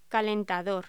Locución: Calentador